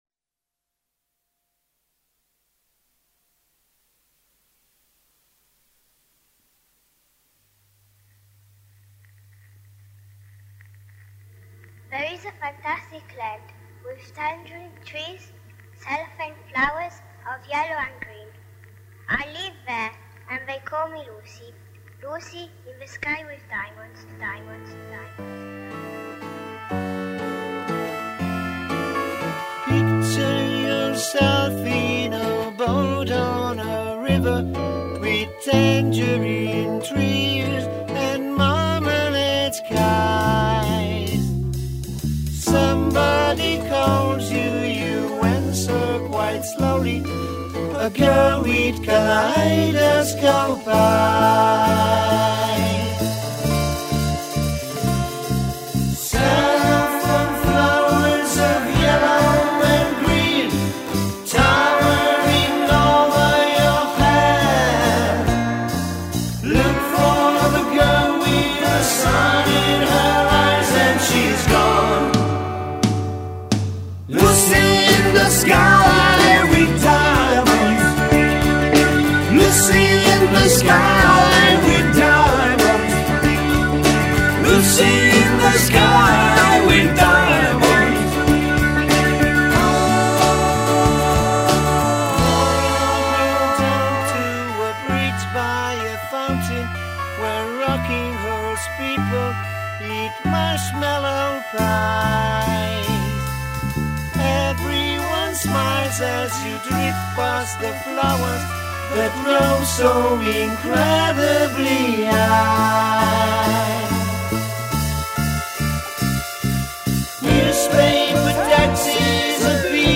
Batteria e cori
Chitarra e voci.
Tastiere e voci.
Chitarra solista.
Chitarra e voce solista.
Chitarra basso e cori.
Chitarra ritmica e cori.